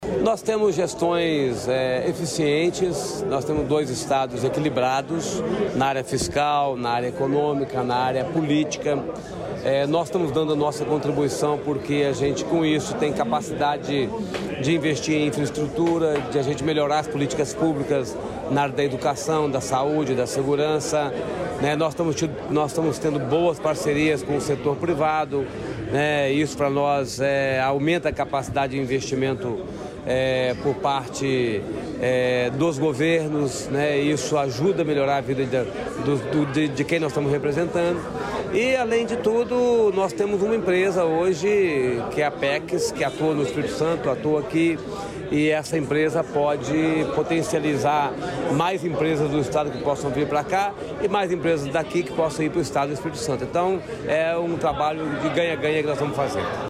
Sonora do governador Renato Casagrande sobre a parceria entre o Paraná e o Espírito Santo